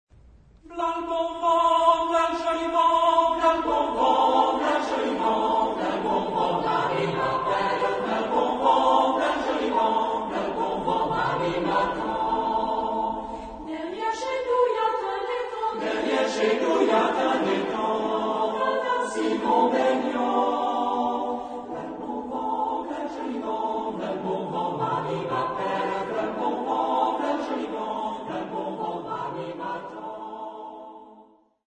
Genre-Style-Form: Secular ; Popular
Mood of the piece: light
Type of Choir: SMAH  (4 mixed voices )
Soloist(s): Soprano (1)  (1 soloist(s))
Tonality: A aeolian